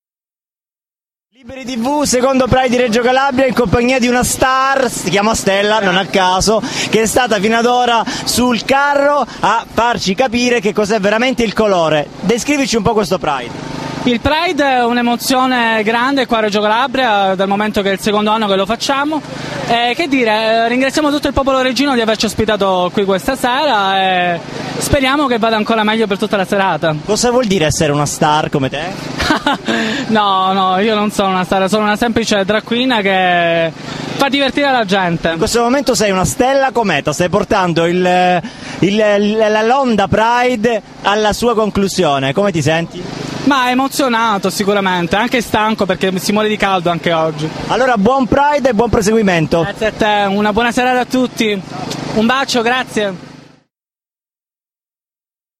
Tappa nazionale dell'Onda Pride - 1 Agosto 2015 Reggio Calabria.